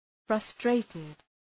Shkrimi fonetik {‘frʌstreıtıd}
frustrated.mp3